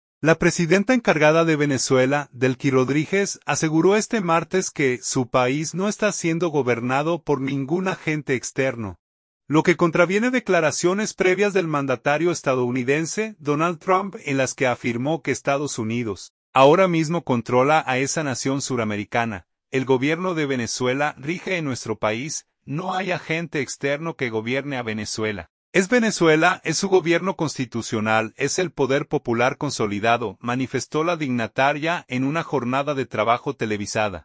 "El Gobierno de Venezuela rige en nuestro país; no hay agente externo que gobierne a Venezuela. Es Venezuela, es su Gobierno constitucional, es el poder popular consolidado", manifestó la dignataria en una jornada de trabajo televisada.